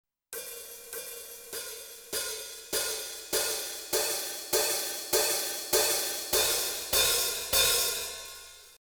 01 はオープンHi-hatのみ。
どうでしょうか？ 音色の変わり目で若干不自然な部分もありますが、
HiHat_01.mp3